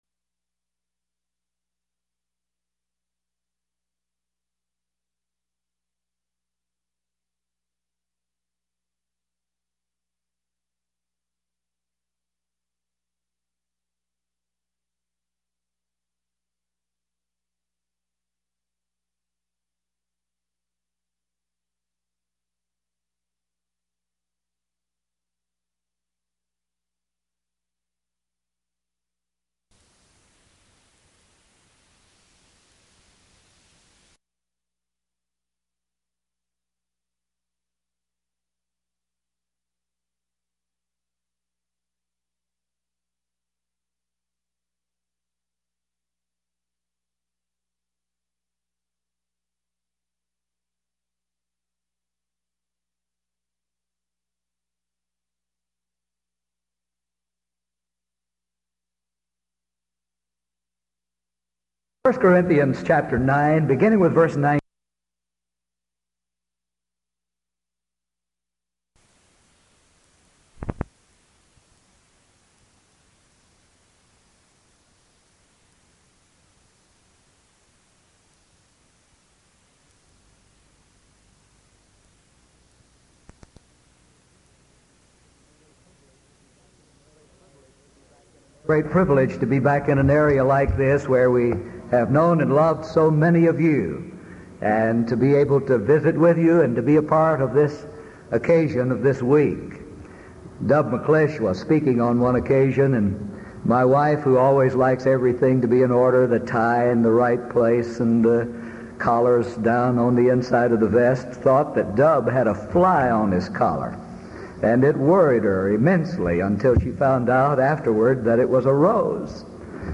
Event: 1982 Denton Lectures
lecture